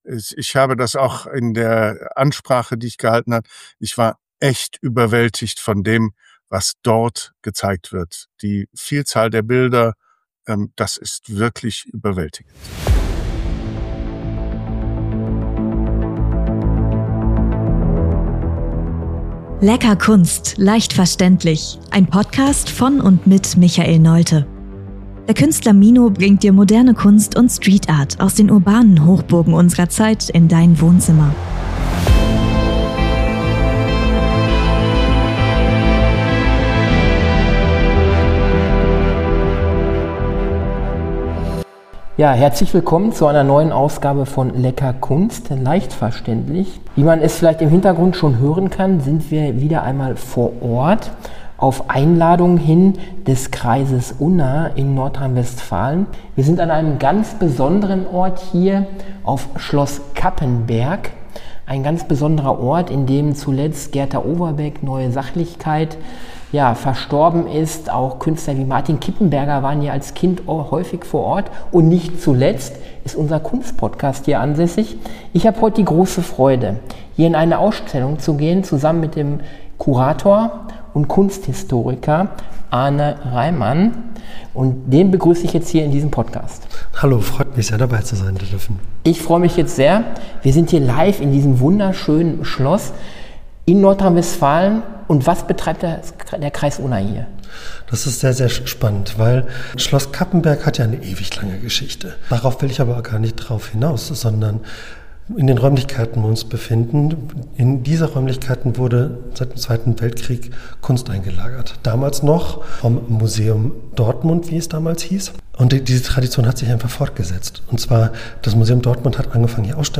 Exklusive Interviews aus dem Museum Schloss Cappenberg